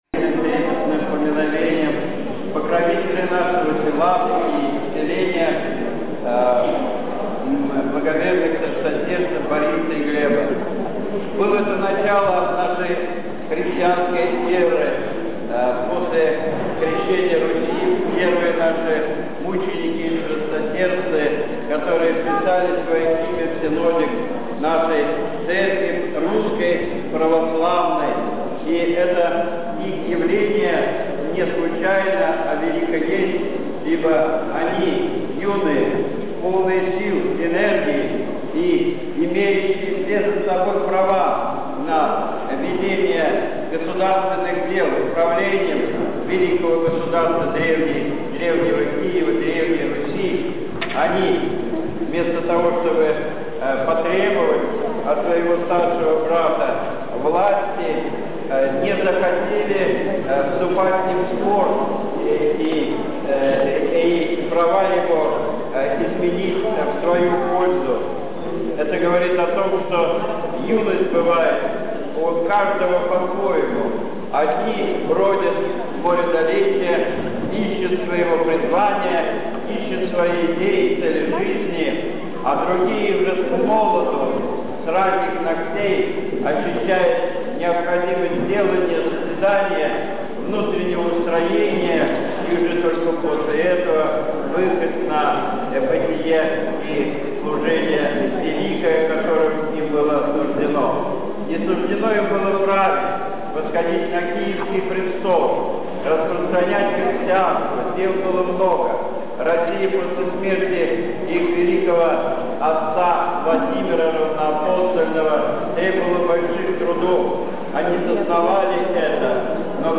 6 августа в церкви Покрова Божией Матери с. Борисово отмечался праздник чтимых святых мучеников благоверных князей Бориса и Глеба, во Святом Крещении Романа и Давида.
Слово в день памяти
Обращение к пастве